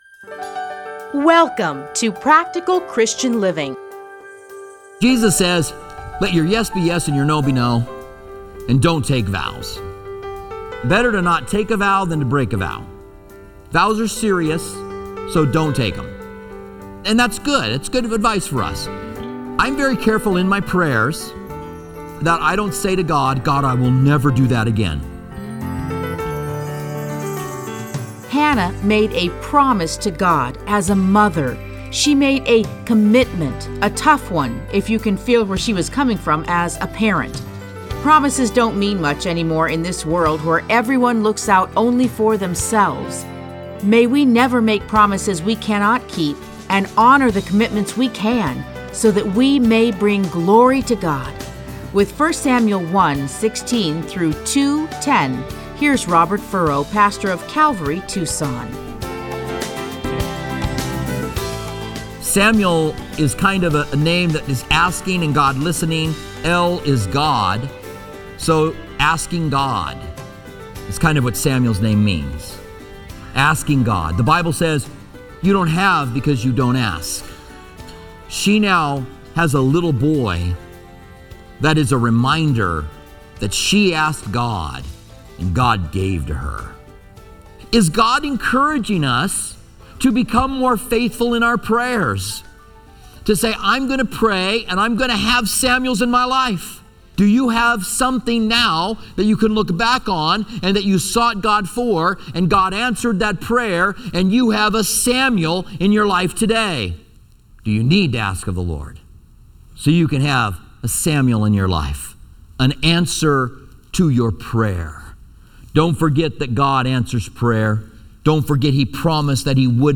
Listen to a teaching from 1 Samuel 1:16-2:10.